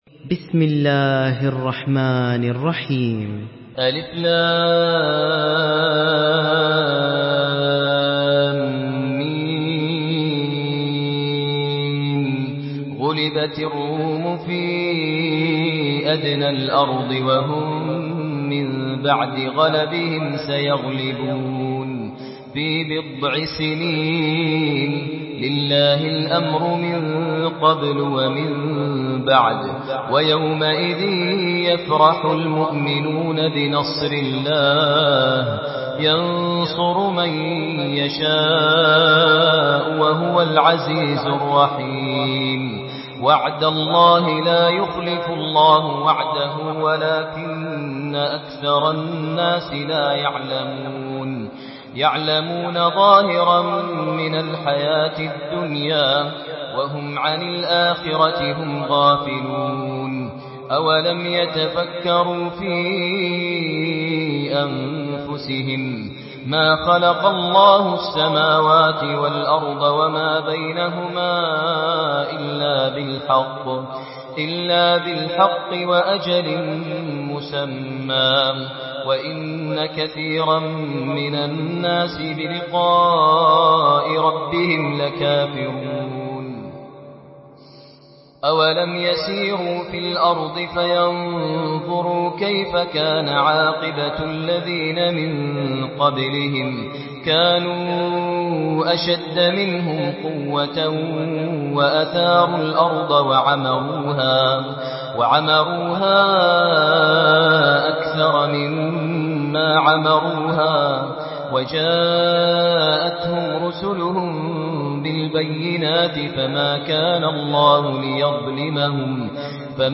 Surah আর-রূম MP3 by Maher Al Muaiqly in Hafs An Asim narration.
Murattal Hafs An Asim